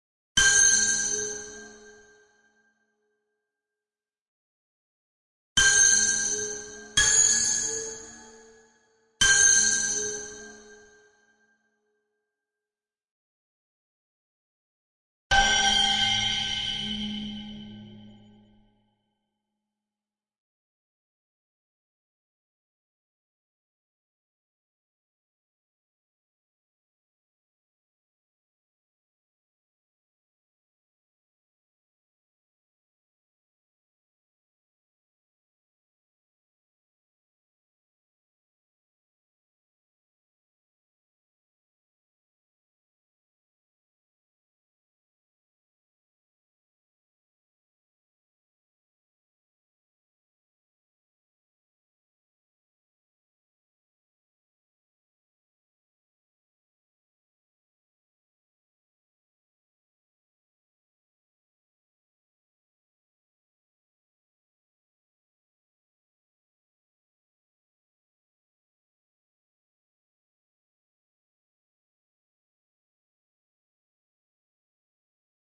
描述：慢下来的心理/恐怖的氛围。
他用RODE NT4 XYstereo话筒接入MOTU Ultralite MK3进行录音。制作了幽灵般的氛围背景和时刻。
用Paulstretch软件制作的效果。
标签： 背景SOU第二 怪异 戏剧 令人毛骨悚然 邪恶 兴奋 害怕 声音 背景 闹鬼 戏剧 恐怖 图片 恐怖 钢琴 氛围 声音效果 ATMOS 噩梦 焦虑 幻觉 电影 地狱 环境 悬念 忌氛围 气氛
声道立体声